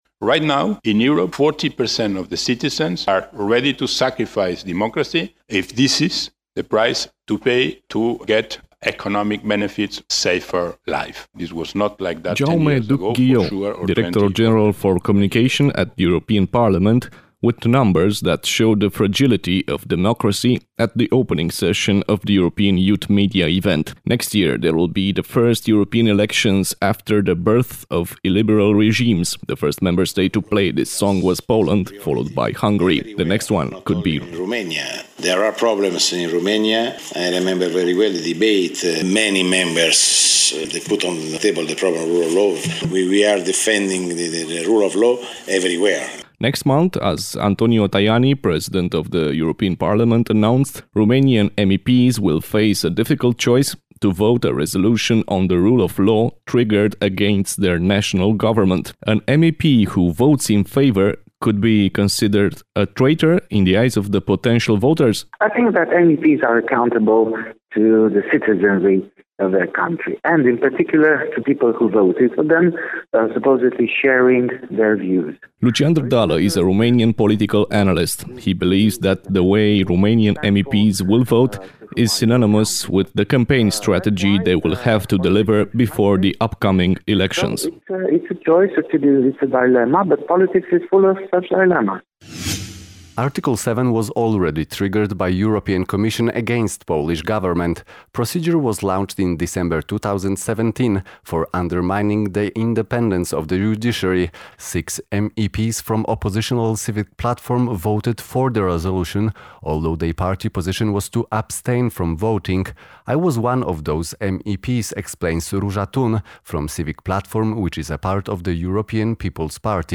au realizat un radioreportaj în acest sens